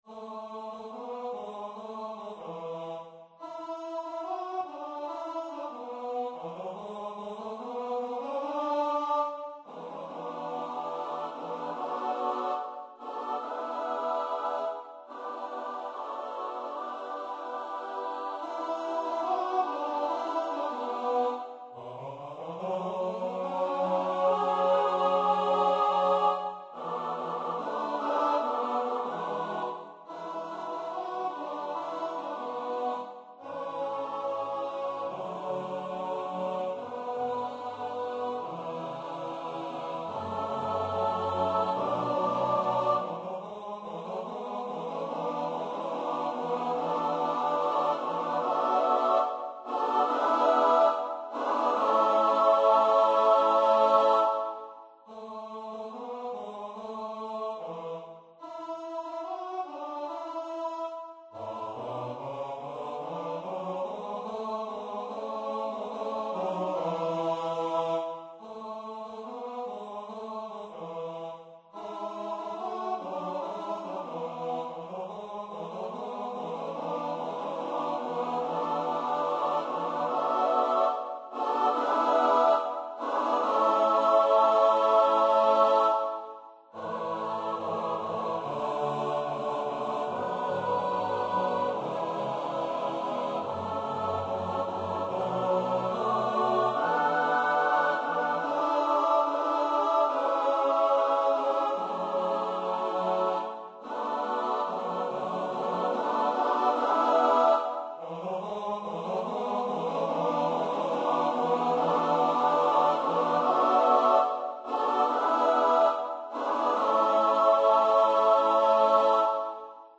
for unaccompanied mixed voice choir
A lovely, modern, lively setting
for six part (SSATBB) unaccompanied mixed voice choir